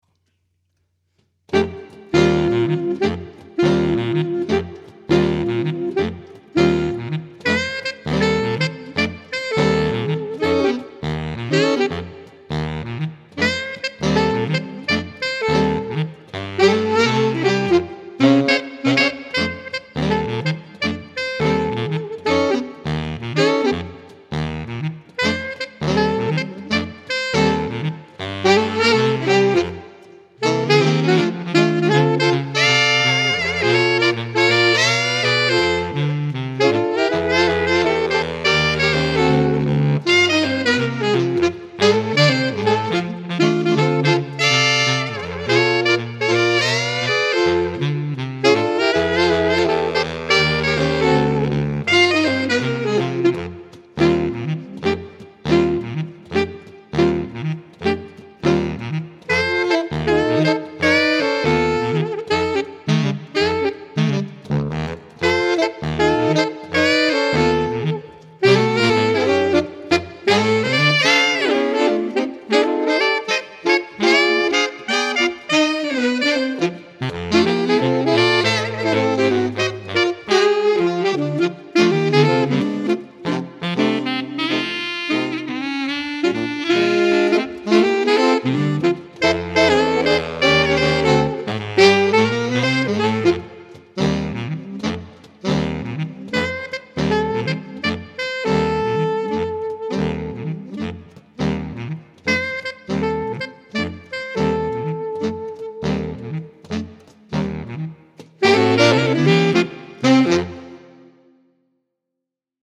Set in a Calypso style
More Saxophone Quartet Music